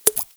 notification sounds